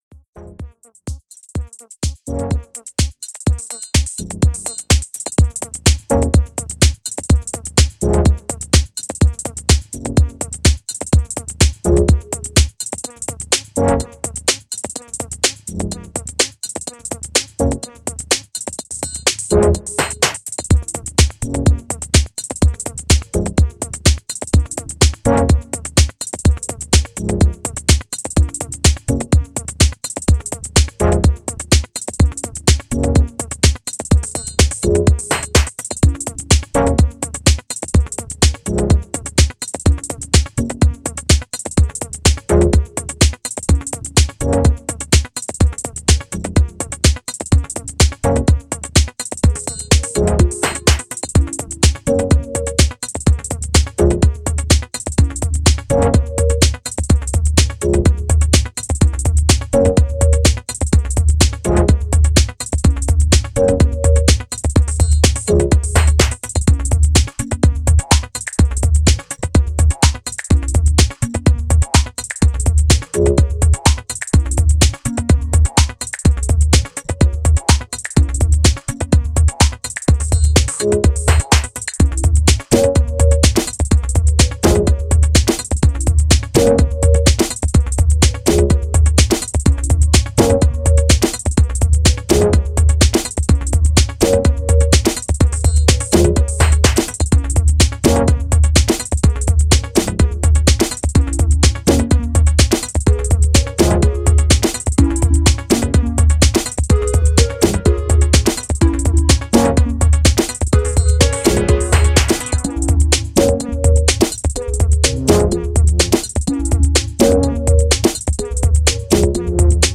Minimal techno